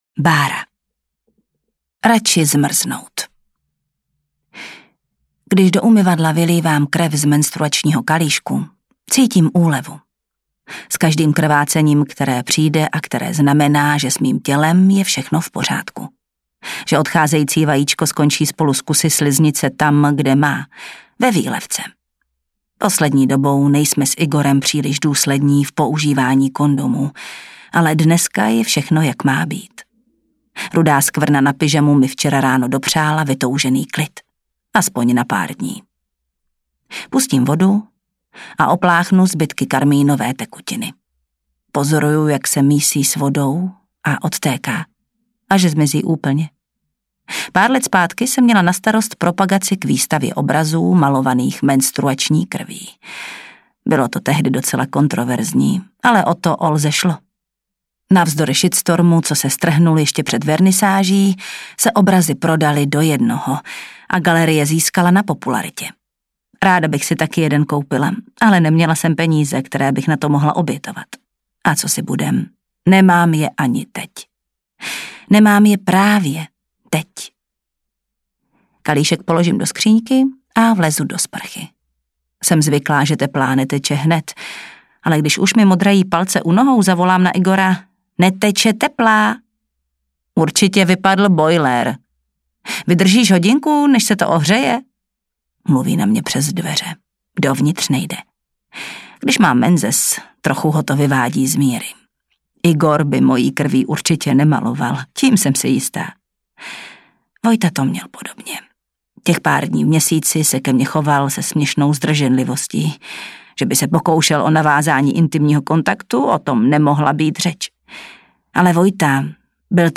Audiobook
Read: Jana Stryková